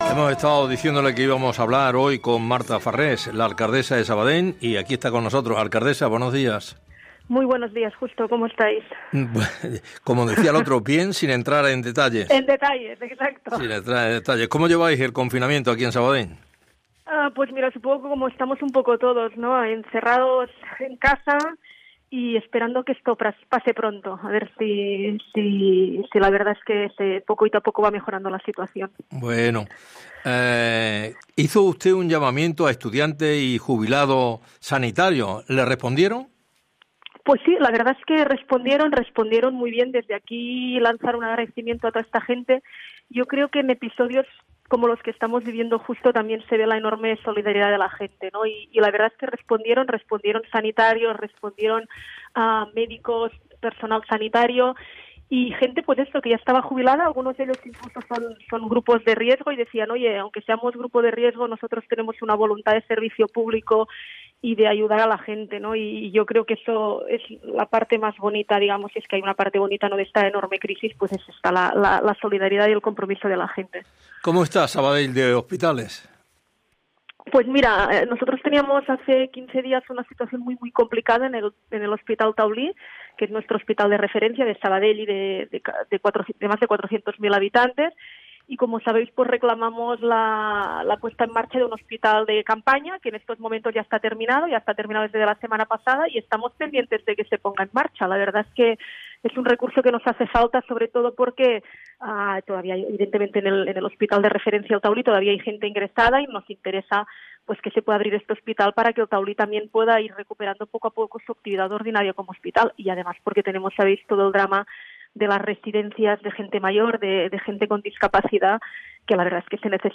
Justo Molinero entrevista a Marta Farrés
entrevista-tlf-alcalde-sabadell.mp3